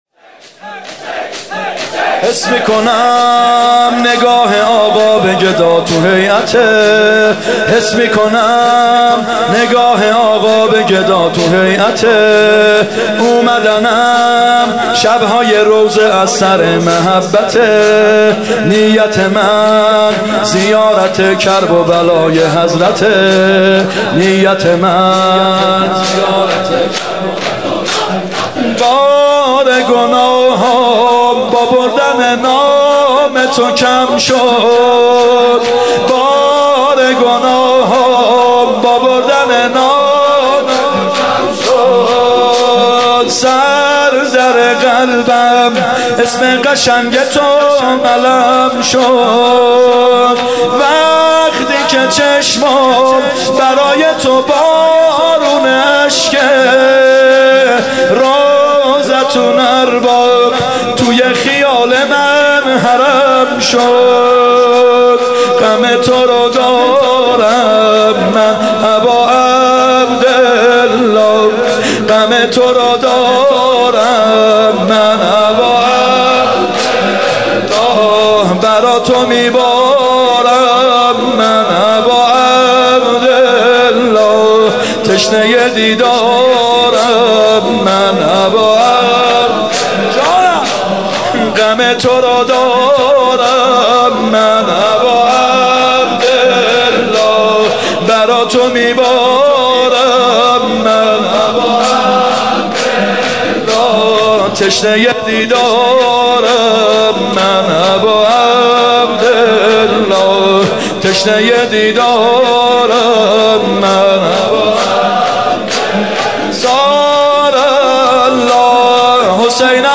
محرم 95 شب اول شور
محرم 95(هیات یا مهدی عج)